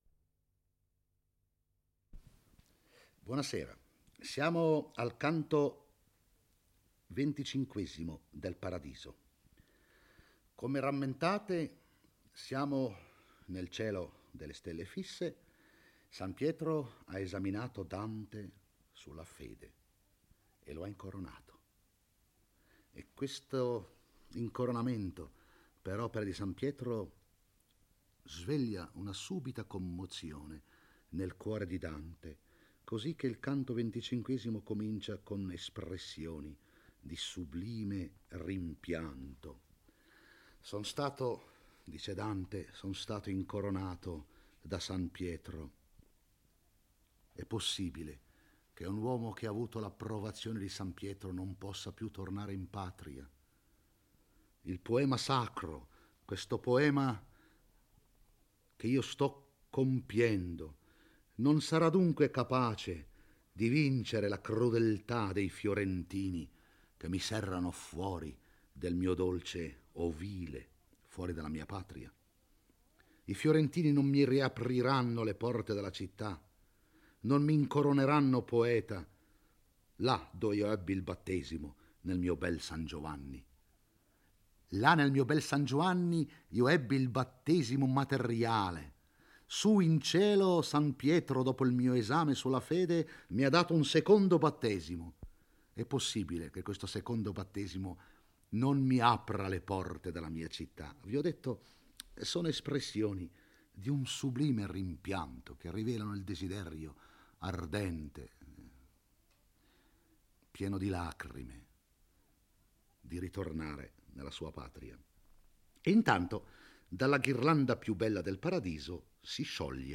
legge e commenta il XXV canto del Paradiso. Dalla medesima corona da cui era uscito San Pietro esce ora lo spirito di Sant'Jacopo, e Beatrice lo prega affinché esamini Dante intorno alla seconda virtù teologale. Il santo domanda dunque al pellegrino che cosa sia la Speranza, in quale misura la possegga e da chi gli sia venuta.